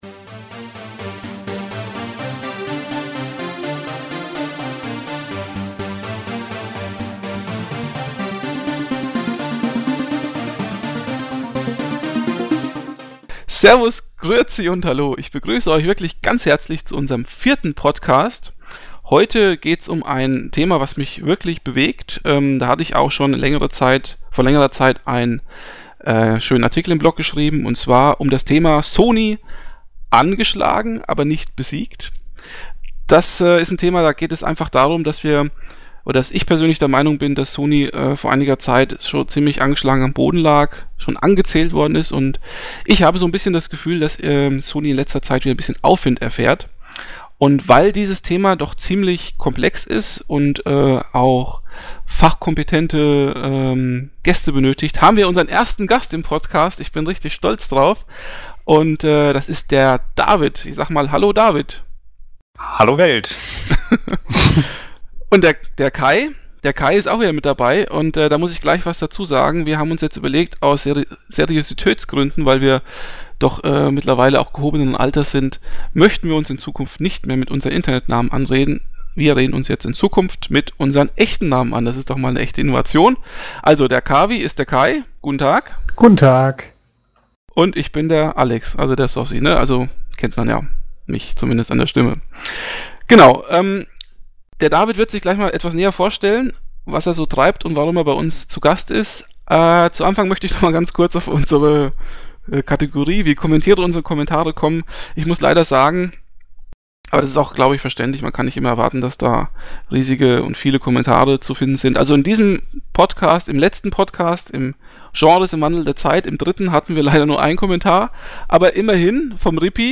Fun Fact: Obwohl nun drei Labertaschen am Start sind, ist Folge 4 der kürzeste Podcast aller bisherigen vier Folgen.